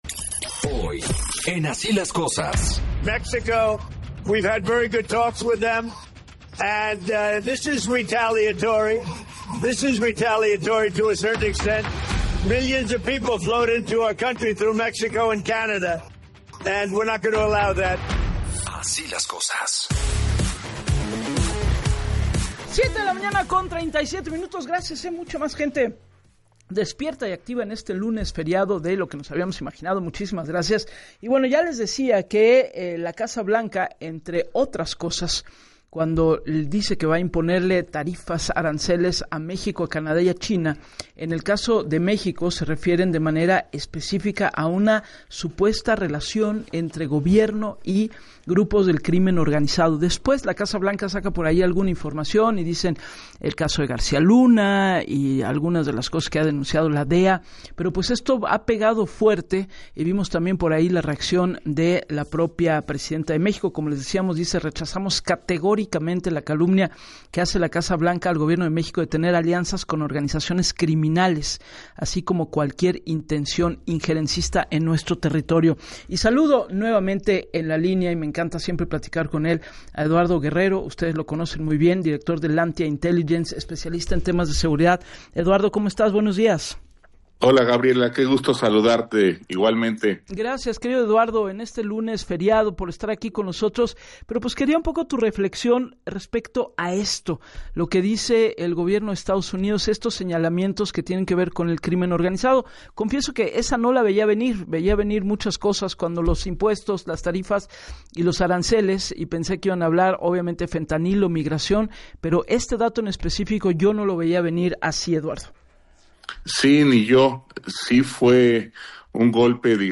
Puntualizó en el espacio de “Así las Cosas” con Gabriela Warkentin, que el gobierno de Donald Trump no fue específico sobre su calificación, “una acusación para calentar ánimos” tal como lo refirió la secretaria de Seguridad Interna de Estados Unidos, “se trata de presionar al gobierno mexicano para que haga un mayor esfuerzo”.